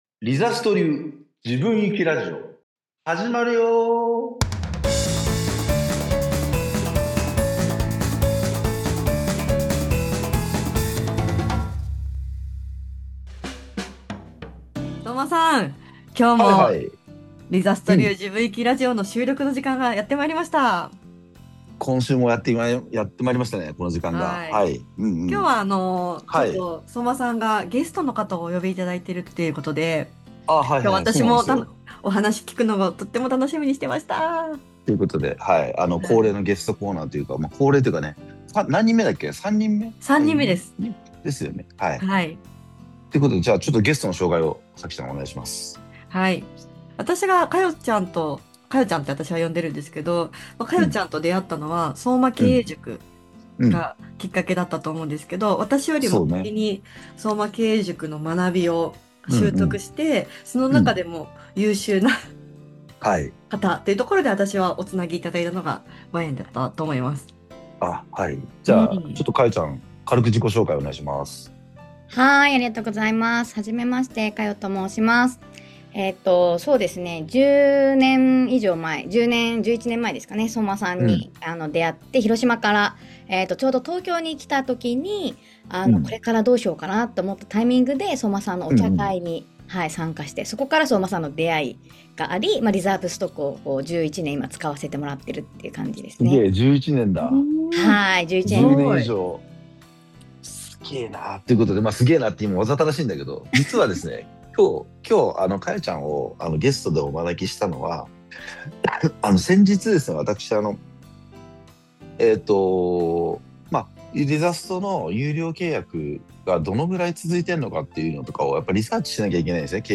🎧 今回のラジオの聴きどころ